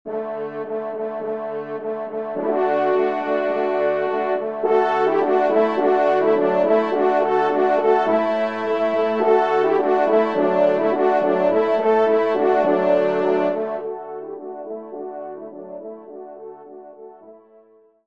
Genre :  Divertissement pour Trompe ou Cor et Piano
ENSEMBLE